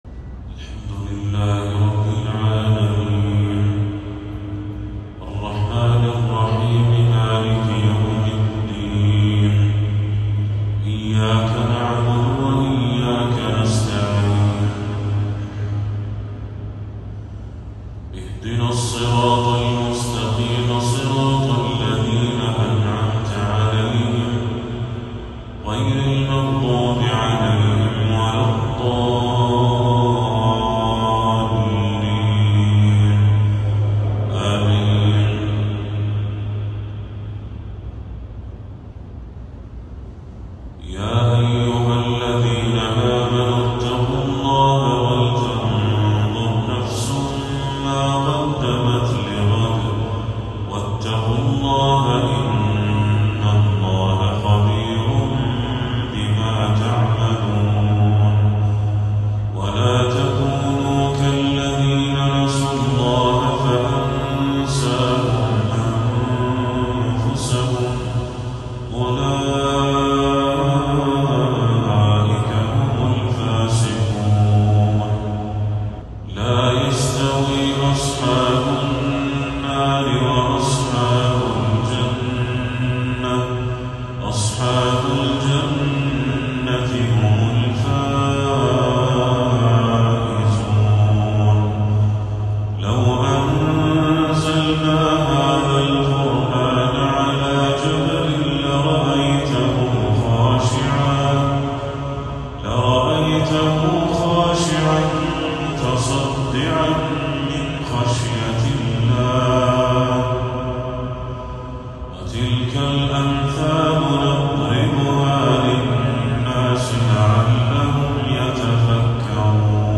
تلاوة عذبة لخواتيم سورتي الحشر والإنسان
عشاء 1 ربيع الأول 1446هـ